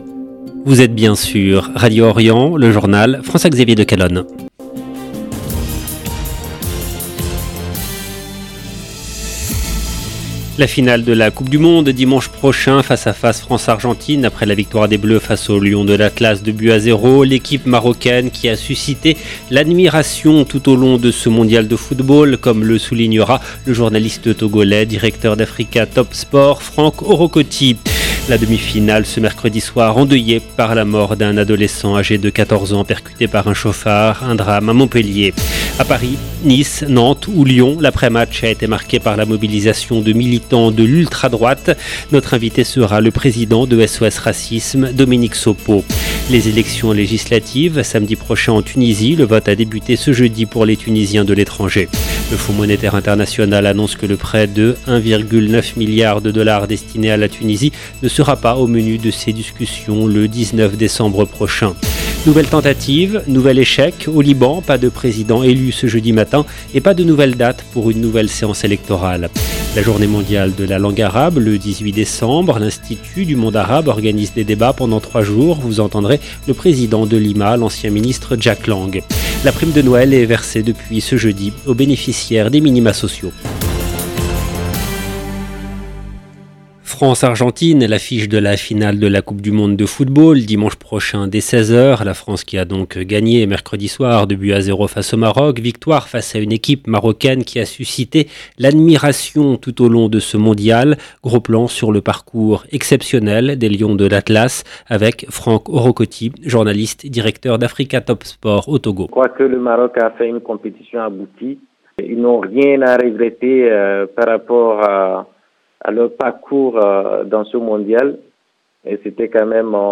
EDITION DU JOURNAL DU SOIR EN LANGUE FRANCAISE DU 15/12/2022
Vous entendrez le président de l'IMA, Jack Lang.